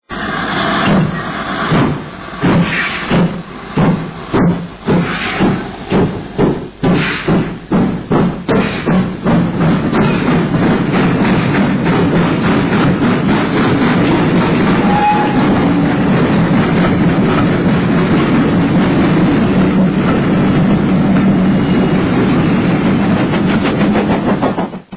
Sounds of Great Western steam locomotives